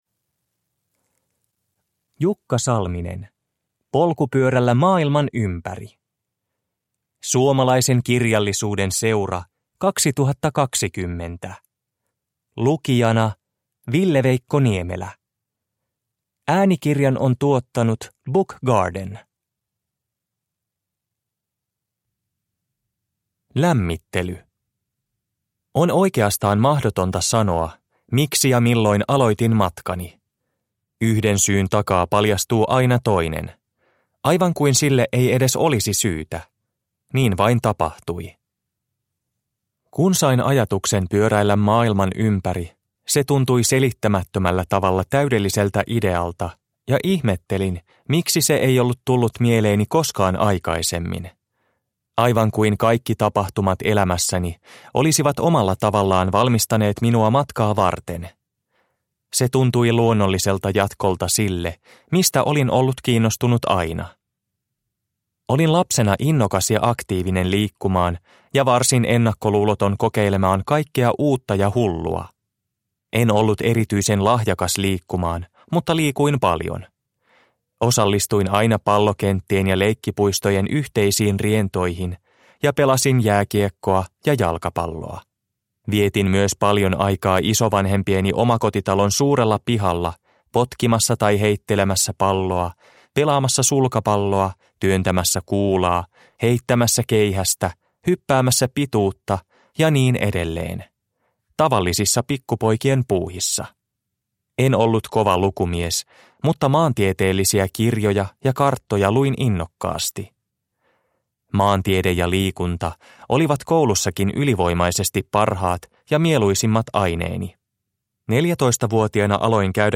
Polkupyörällä maailman ympäri – Ljudbok – Laddas ner